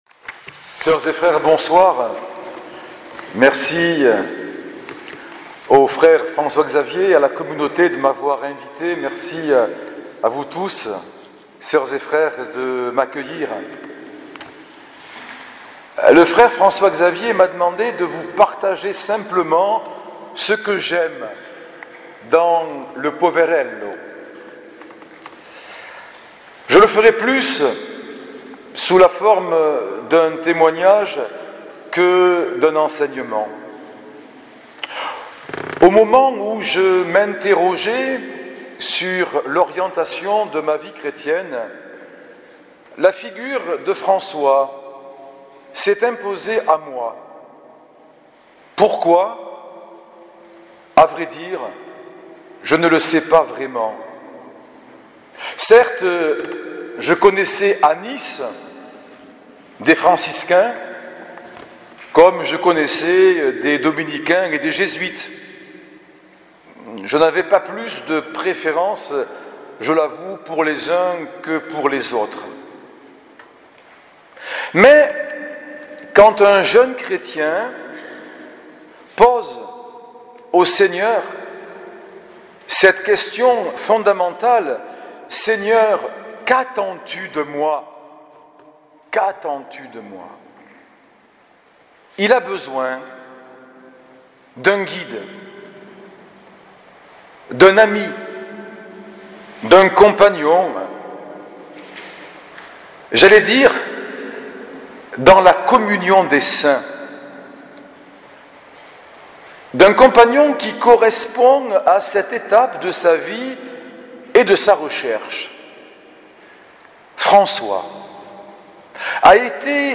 Aujourd’hui, nous poursuivons notre série ‘témoignage’ avec l’évêque de Perpignan – Elne, Mgr. Turini. Il est venu le 2 avril 2017 dans notre couvent Saint Bonaventure de Narbonne nous proposer une prédication de Carême centrée sur Saint François qui est, pour lui, un saint très actuel, proche de tous, joyeux d’avoir épousé Dame Pauvreté, capable de s’émerveiller et de vivre la fraternité.